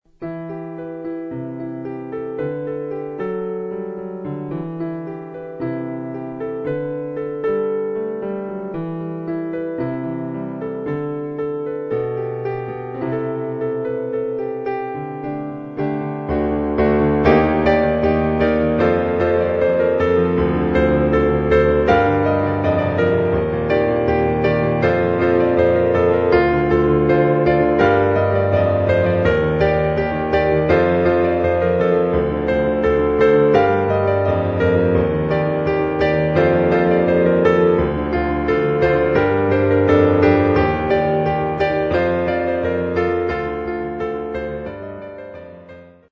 I think this is an interesting tune, but I can't shake the feeling that I played it really poorly.